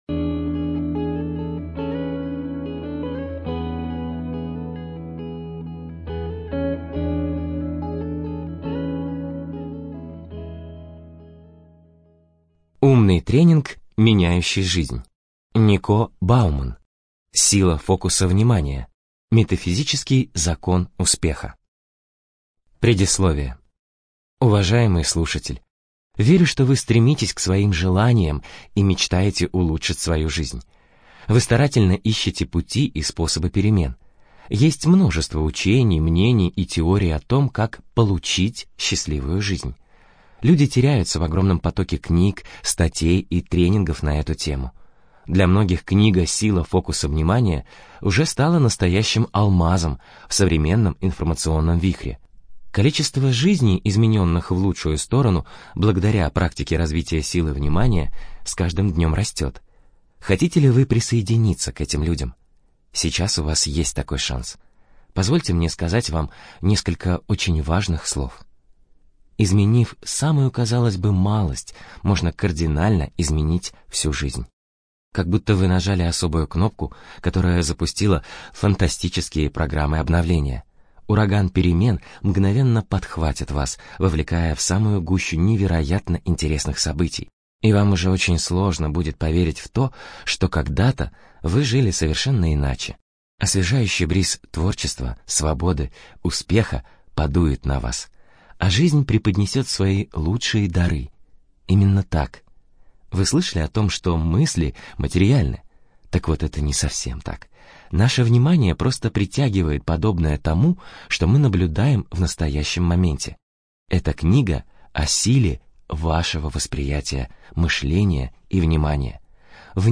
ЖанрЭзотерика, Психология